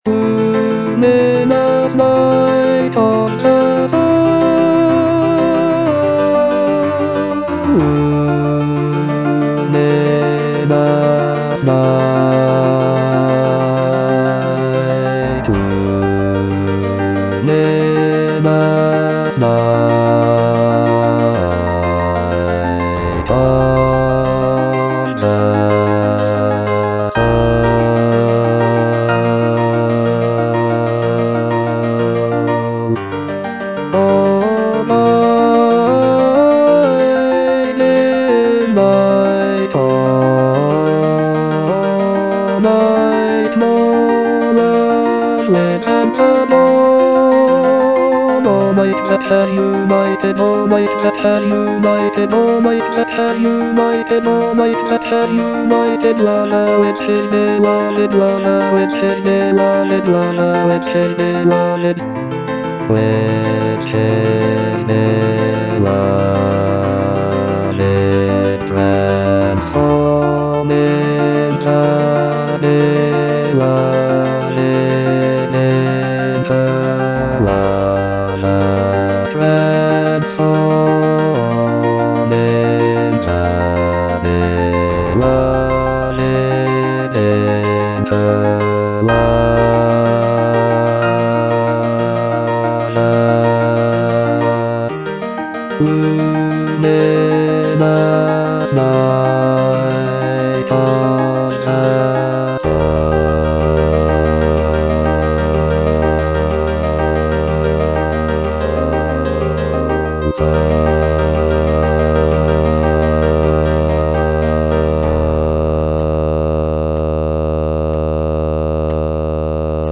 (bass II) (Voice with metronome) Ads stop
choral work